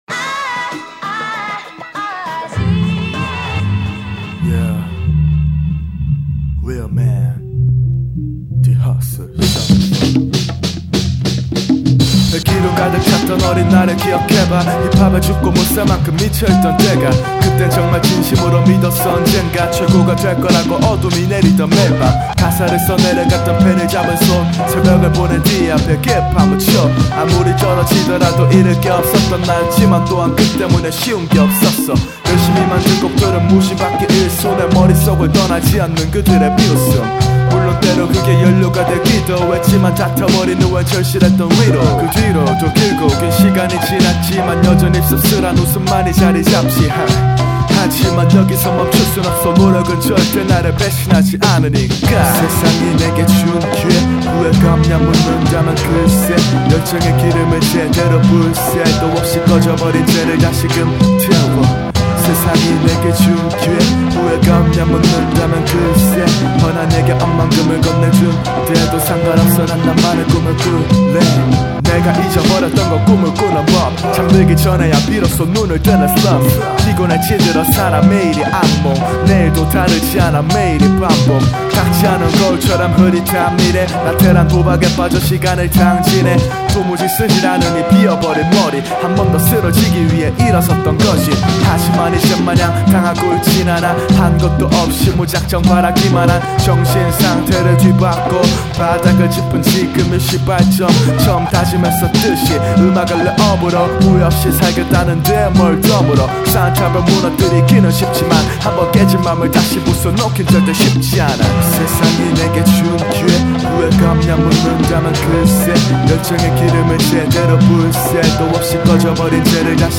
목소리가 깊고 매력있네요
비트와 좋은 조화를 이룬것같습니다